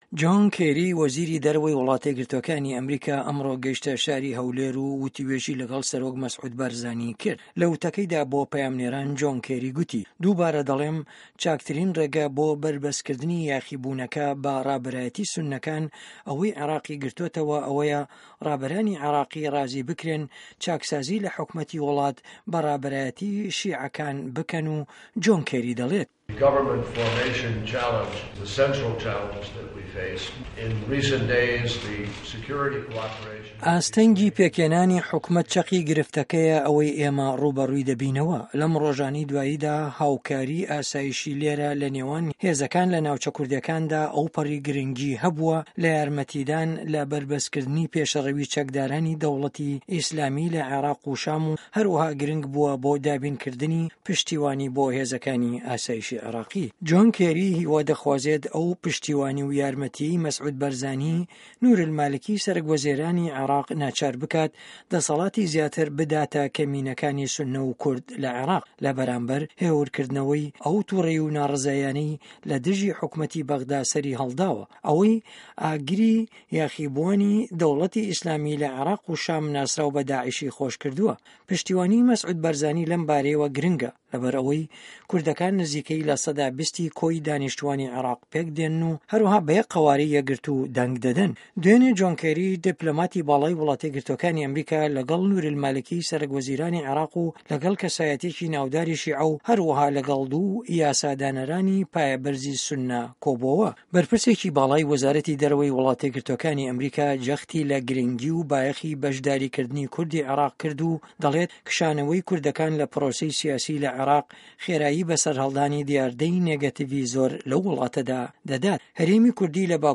ڕاپـۆرتی عێراق